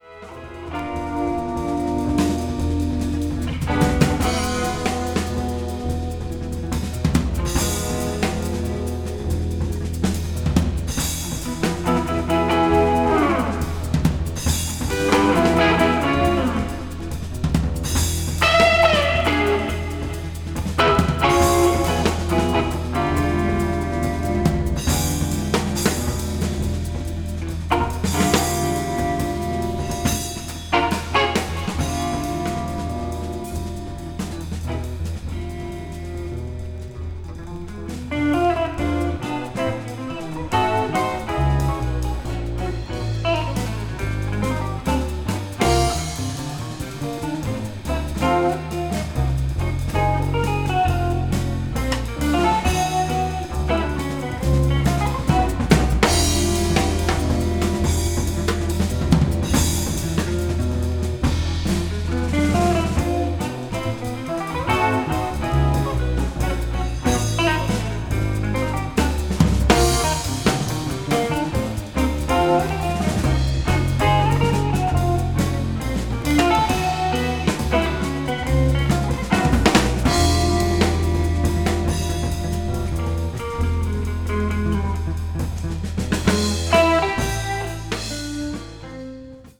contemporary jazz   guitar trio